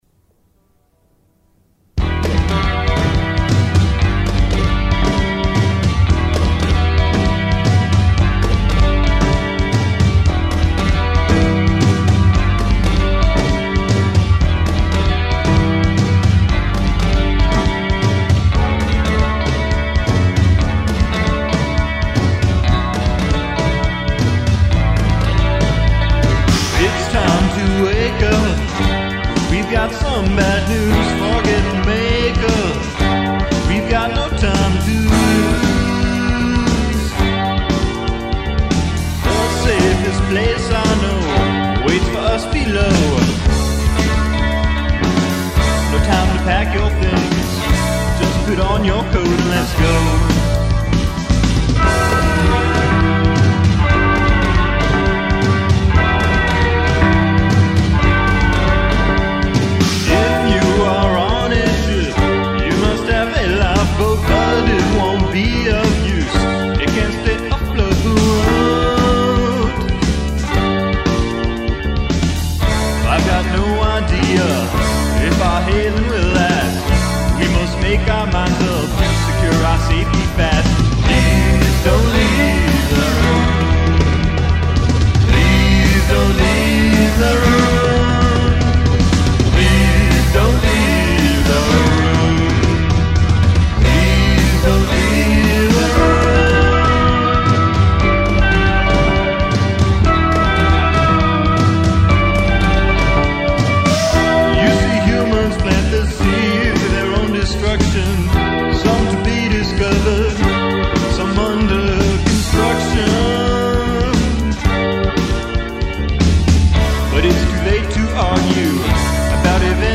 vocals & guitar
keyboards